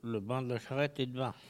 Localisation Sallertaine
Catégorie Locution